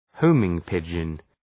Shkrimi fonetik{‘həʋmıŋ,pıdʒən}